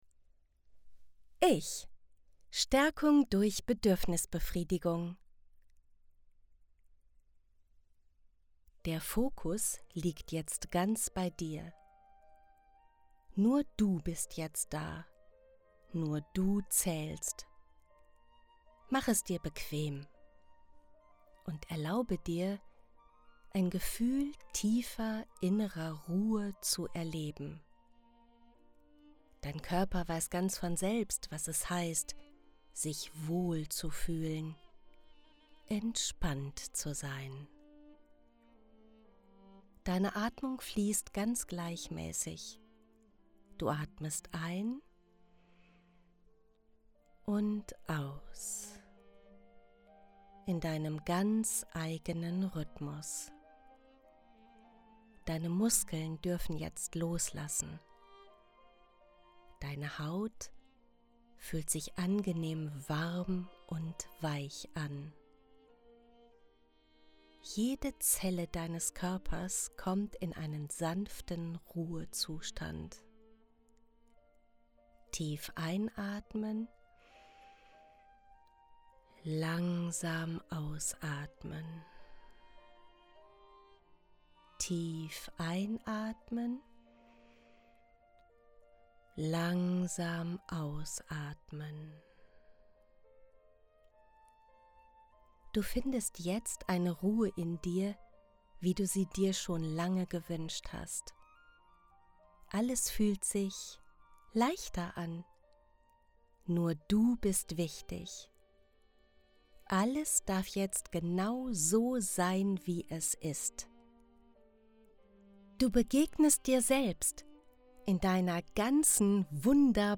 Ich-Starkung-durch-Bedurfnisbefriedigung_Musik.mp3